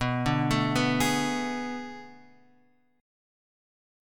B Minor 13th